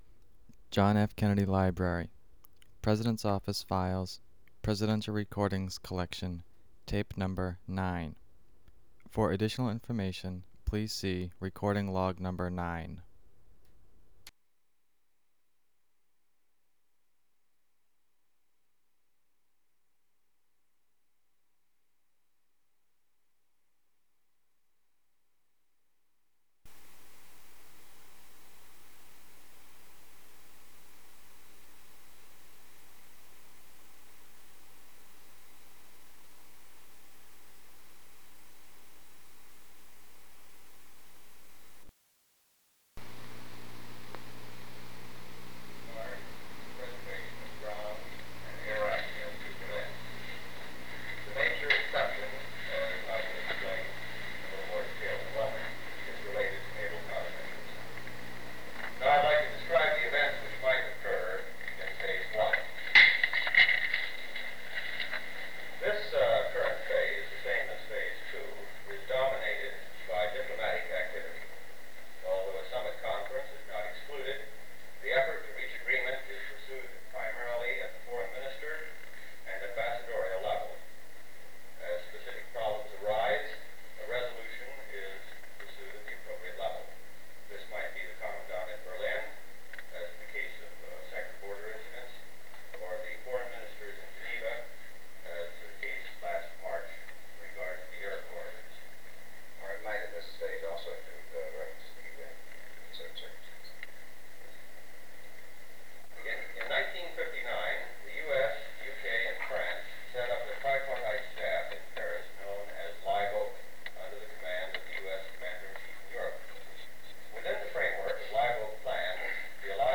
Secret White House Tapes | John F. Kennedy Presidency Meeting on Berlin (cont.)